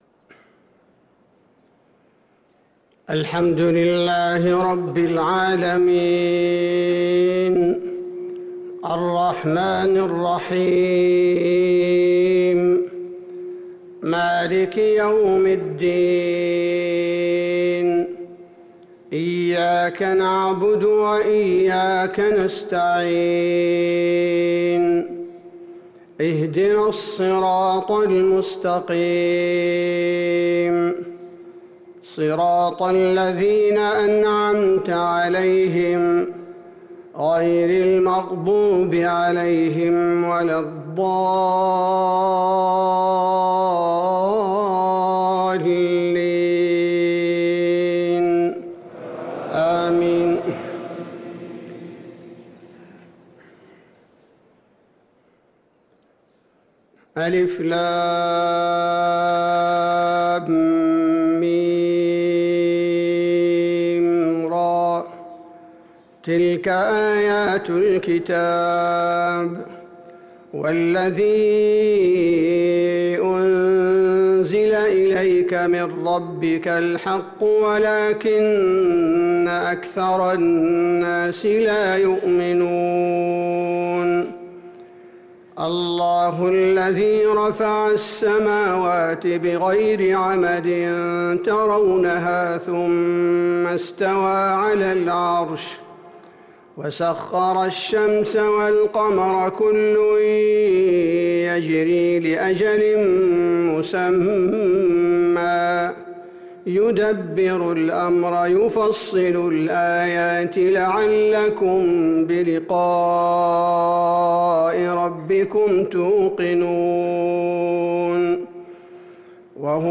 صلاة الفجر للقارئ عبدالباري الثبيتي 7 جمادي الآخر 1445 هـ